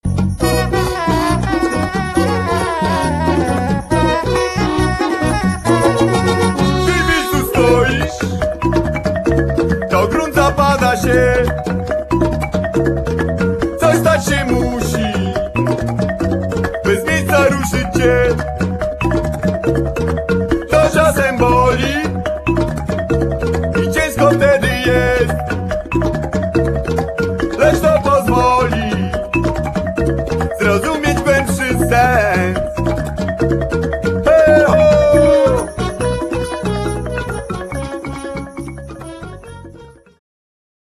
flety, lira korbowa, drumla, tarogato, śpiew gardłowy, śpiew